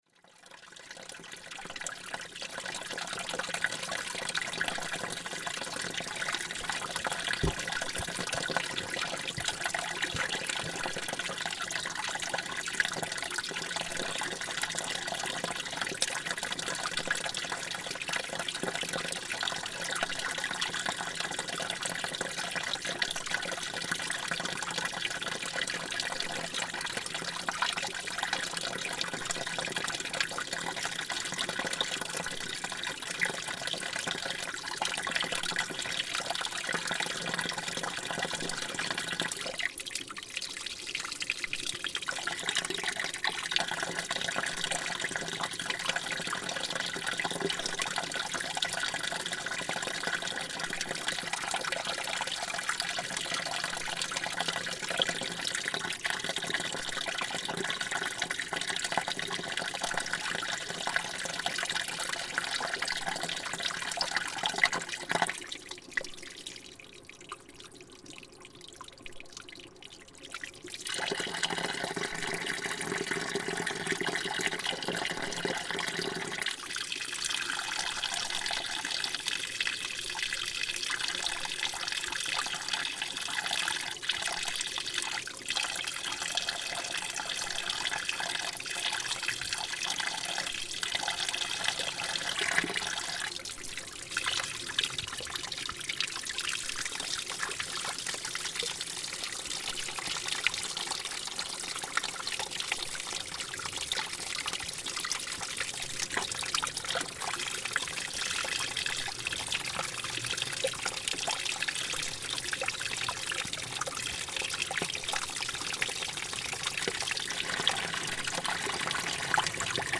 This series of performances takes place in isolated areas in the American West where the artist encounters moving water.
(stereo audio, 22 mb)